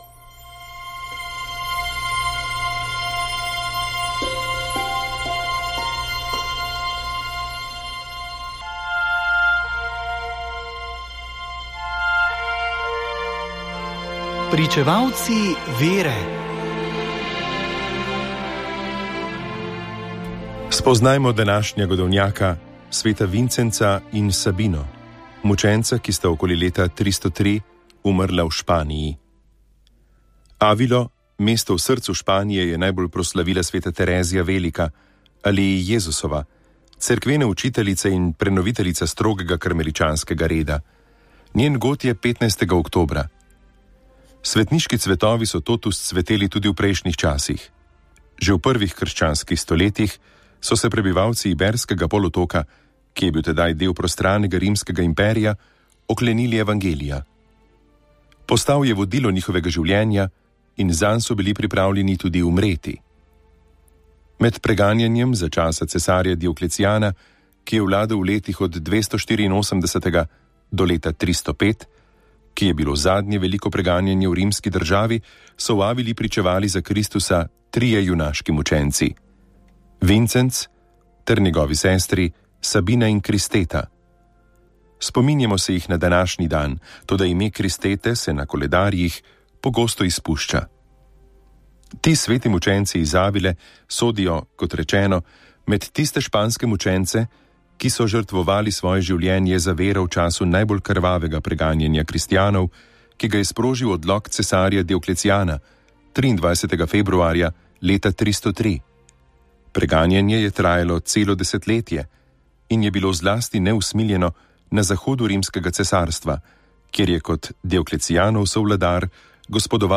Iz knjige Svetnik za vsak dan Silvestra Čuka se vsak dan na Radiu Ognjišče prebira o svetniku dneva.